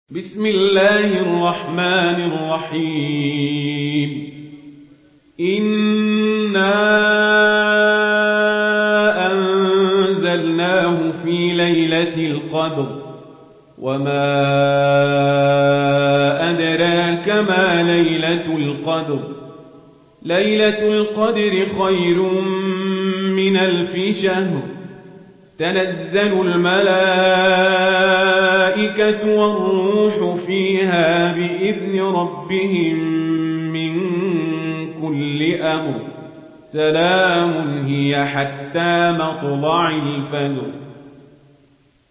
Warsh An Nafi narration
Murattal Warsh An Nafi